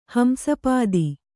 ♪ hamsa pādi